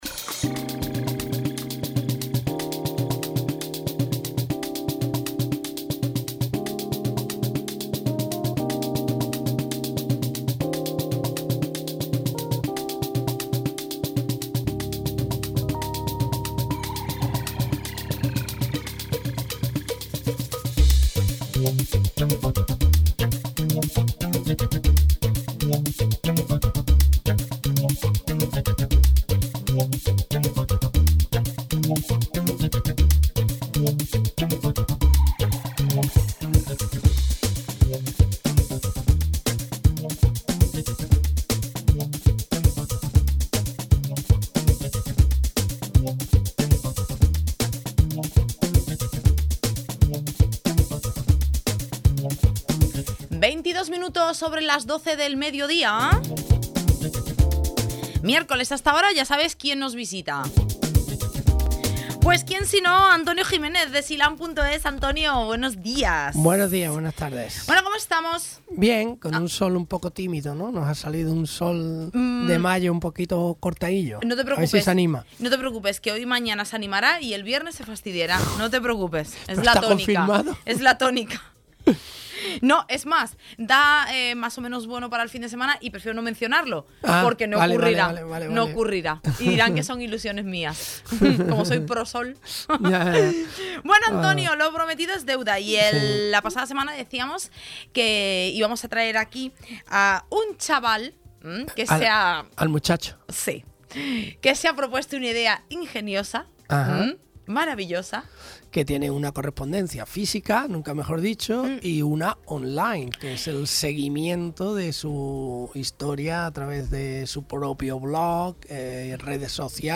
Ha sido una intervención corta que se me ha pasado en un suspiro.